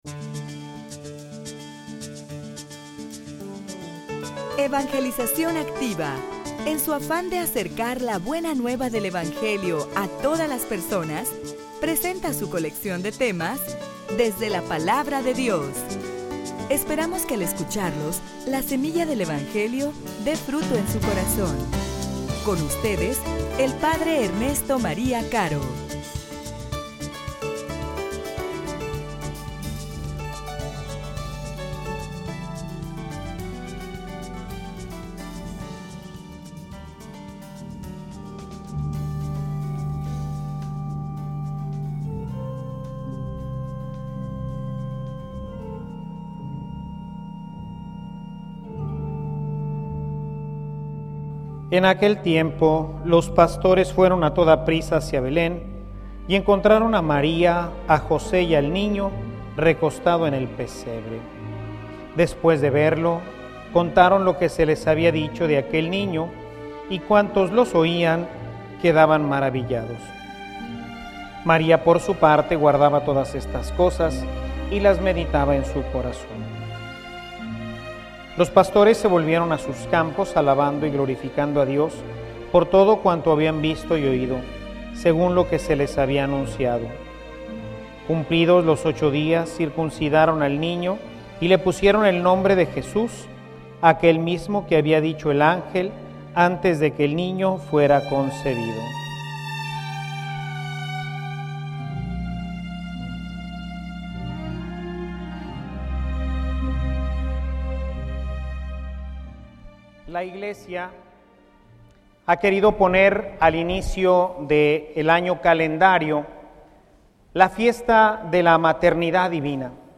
homilia_Maria_madre_de_Dios_y_madre_nuestra.mp3